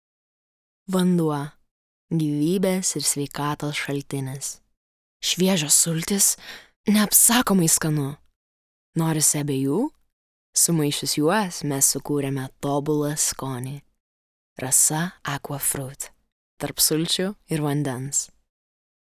Lithuanian, Female, 20s-30s